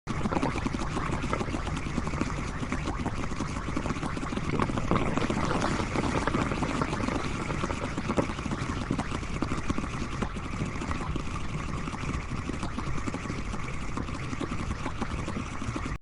Tar Pit Sizzle
Tar Pit Sizzle is a free sfx sound effect available for download in MP3 format.
yt_d-lZRVwvhK8_tar_pit_sizzle.mp3